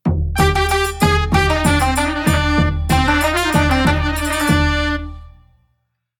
🎻ＢＧＭをご用意しました。
Jingle_Retro_Chindon_BPM95.mp3